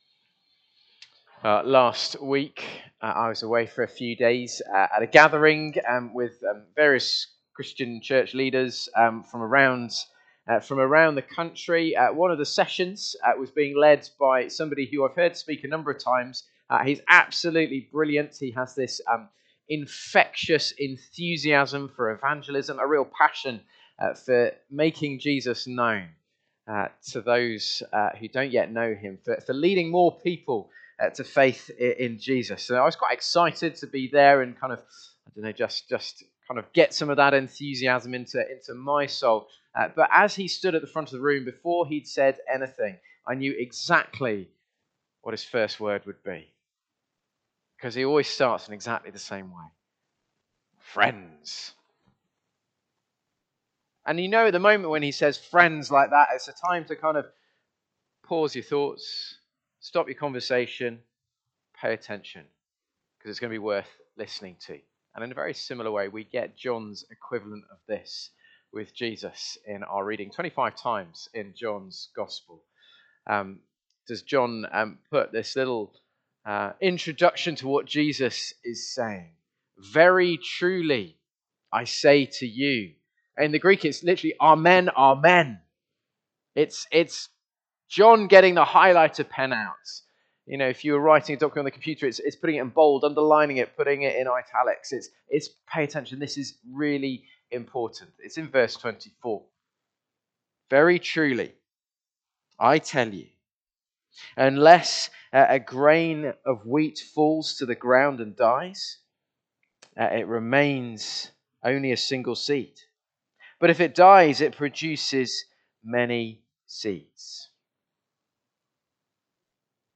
Sermons from St Luke's, Thurnby